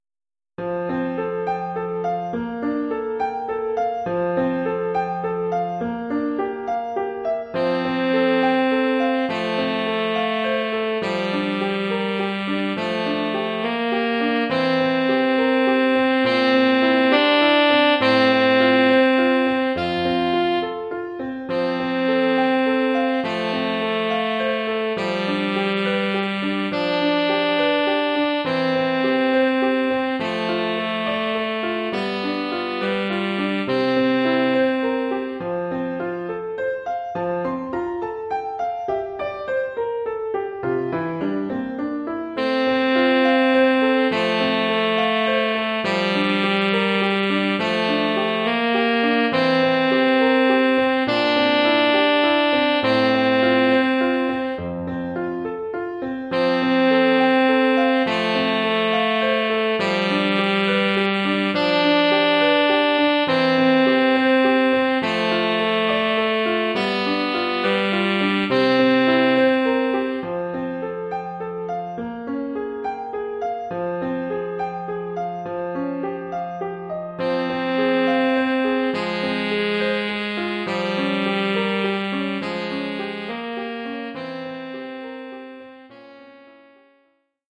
Oeuvre pour saxophone sib et piano.